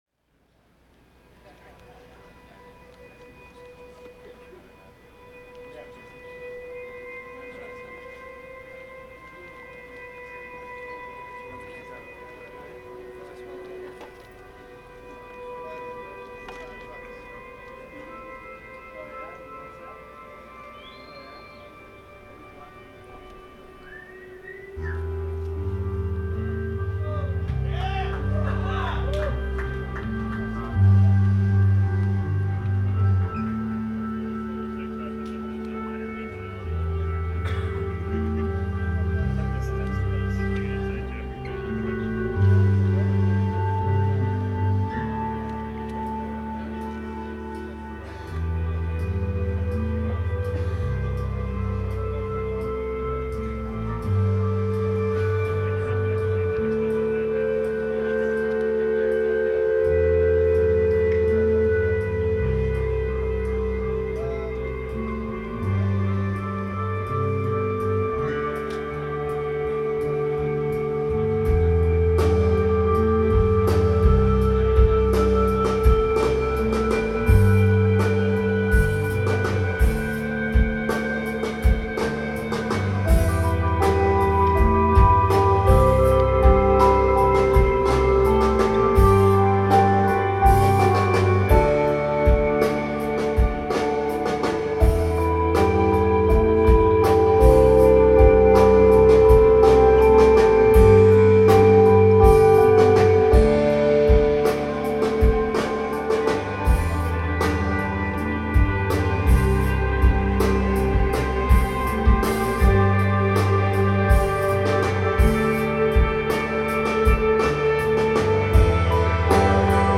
Live at Avalon
Boston, MA